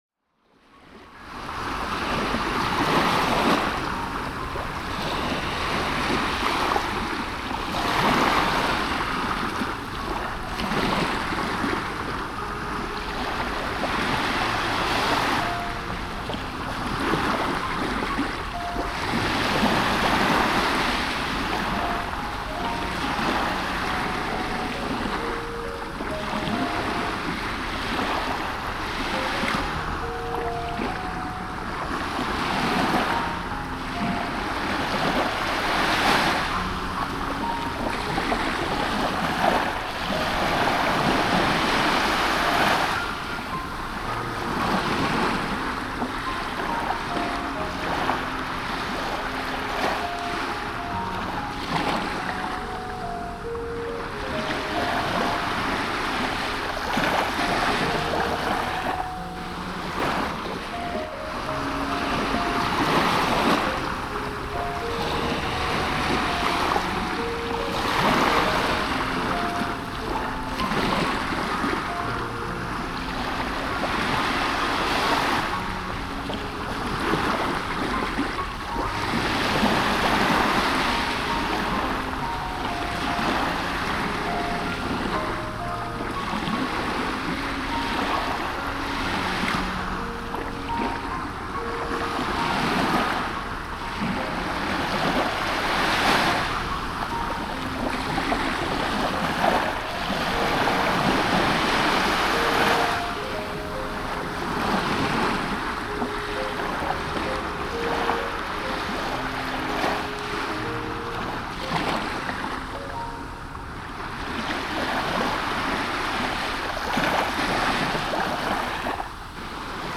【夏の音ASMR】圧倒的癒しの睡眠・作業用環境音【海/花火/川/風鈴/雨】
02：夕日煌めく海の波音.m4a